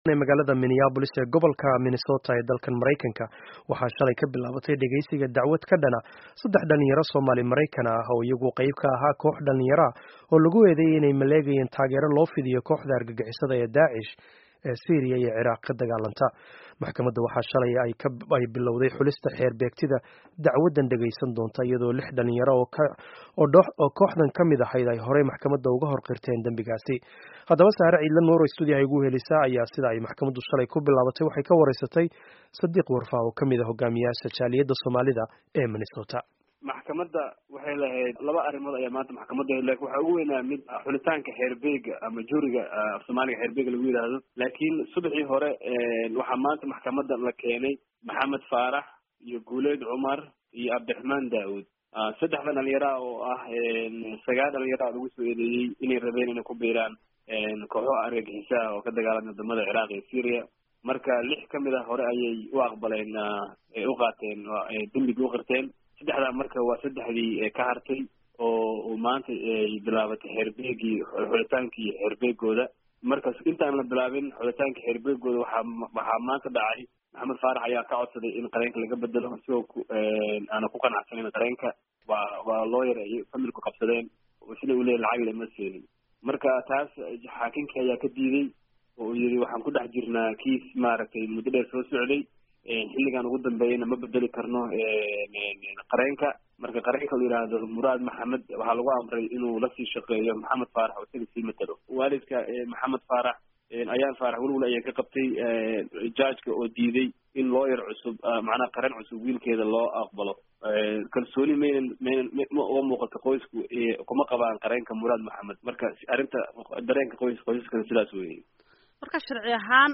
Dhageyso wareysiga Maxkamadda ka bilaabatay Minnesota